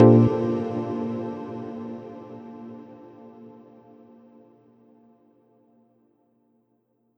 menu-options-click.wav